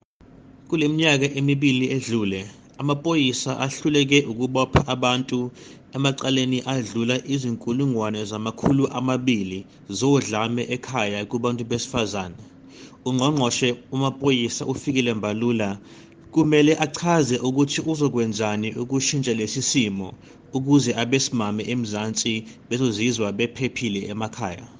Issued by Zakhele Mbhele MP – DA Shadow Minister of Police
Please find the attached sound bites in and